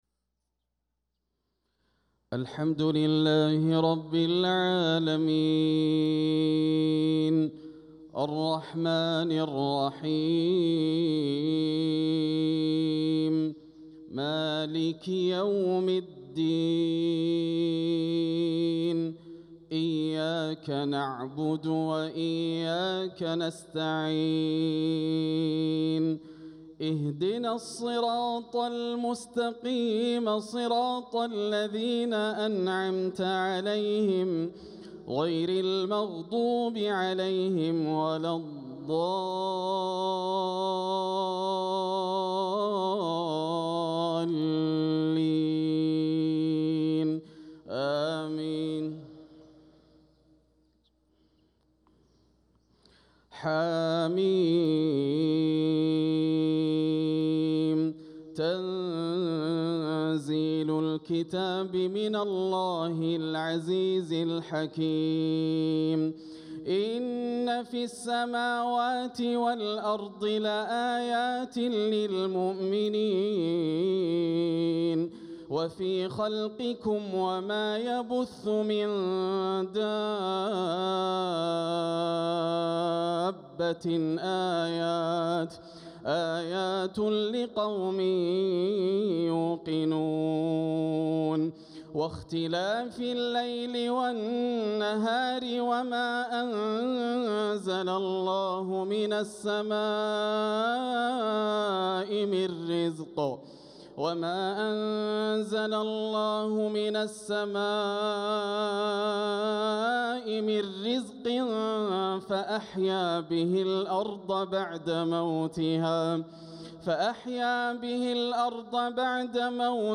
صلاة الفجر للقارئ ياسر الدوسري 6 صفر 1446 هـ
تِلَاوَات الْحَرَمَيْن .